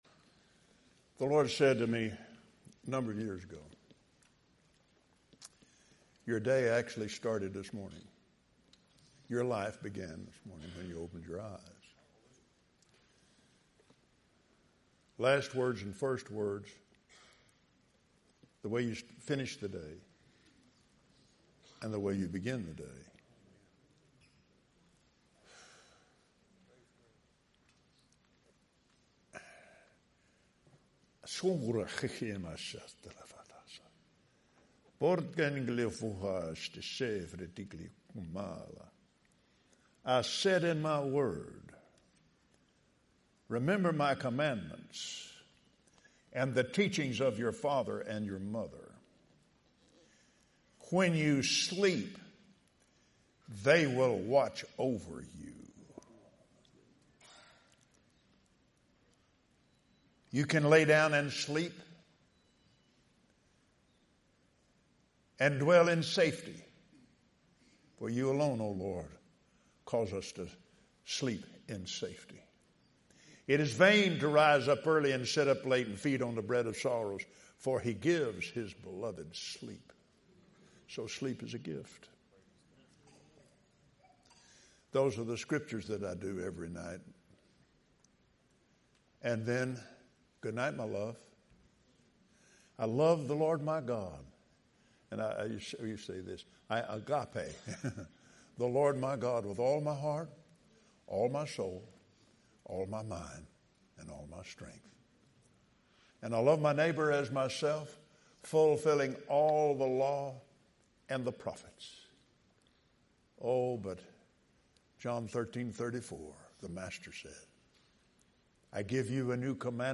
*Recorded LIVE at 2025 Branson Victory Campaign, April 3rd, 2025